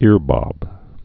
(îrbŏb)